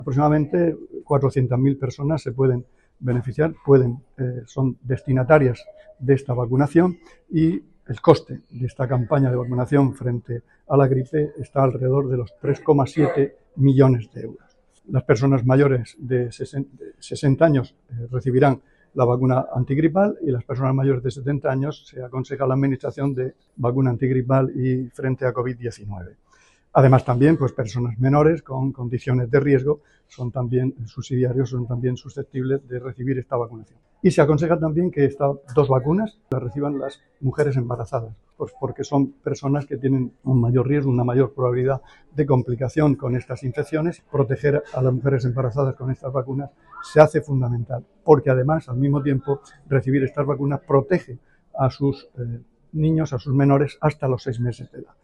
Declaraciones del consejero de Salud, Juan José Pedreño, sobre la campaña de vacunación estacional que comienza hoy en los centros de salud.
El consejero de Salud, Juan José Pedreño, visita el centro de salud de Zarandona, con motivo del inicio de la vacunación antigripal y el Covid-19 a la población de riesgo.